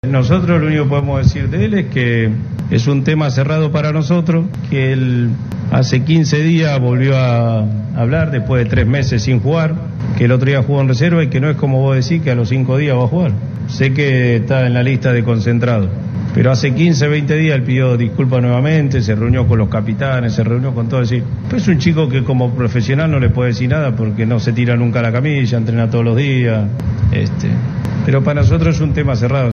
Juan-Roman-Riquelme-director-deportivo-de-Boca-Junors-en-TNT-Sports..mp3